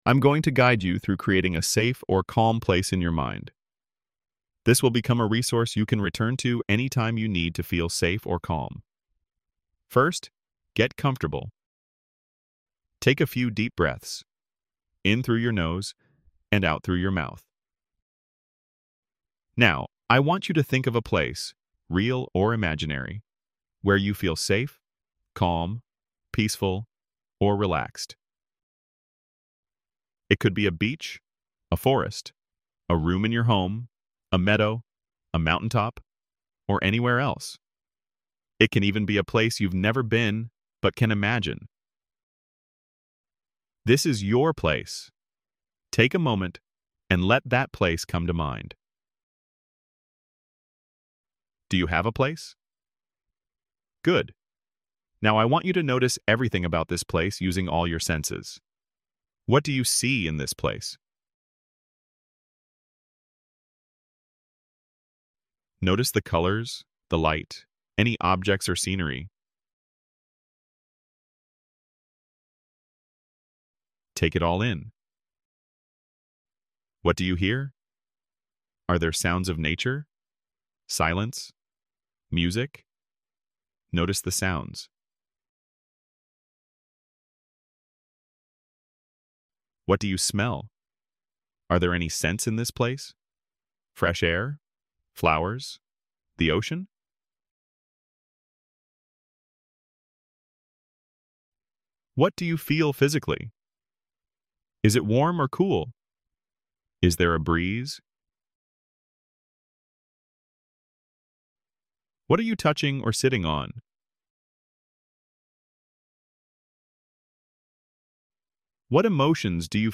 Free audio-guided exercises for EMDR Phase 2 preparation